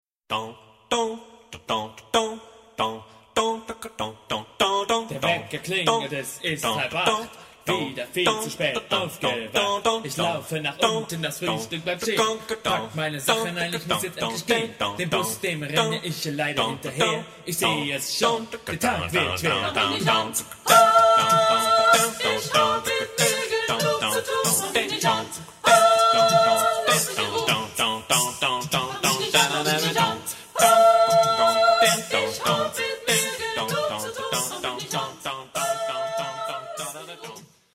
Epoque: 20th century
Genre-Style-Form: Canon ; Rap ; Ostinato ; Secular
Type of Choir: SAM  (3 mixed voices )